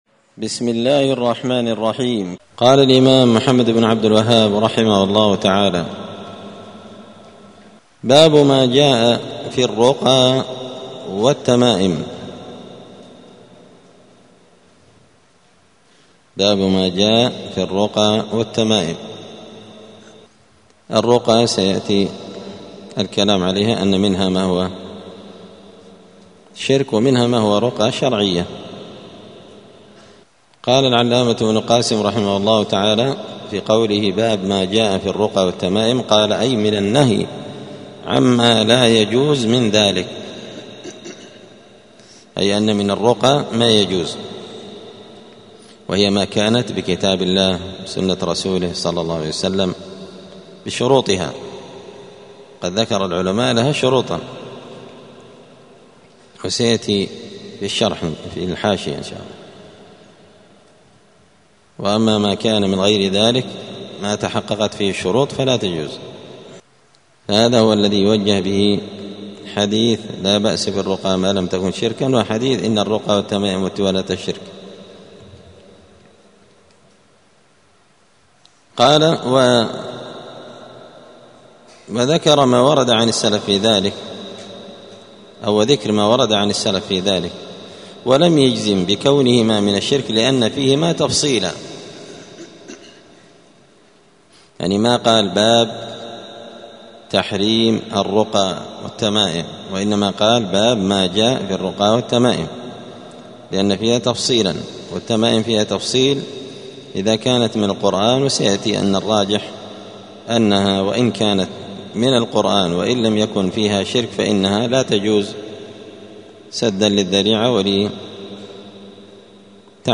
دار الحديث السلفية بمسجد الفرقان بقشن المهرة اليمن
*الدرس الحادي والثلاثون (31) {الباب الثامن باب ما جاء في الرقى والتمائم…}*